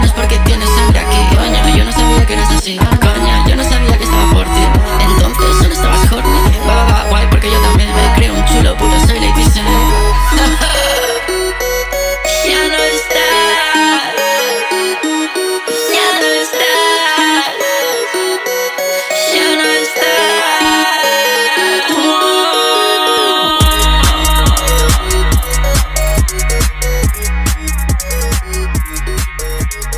Dance Electronic
Жанр: Танцевальные / Электроника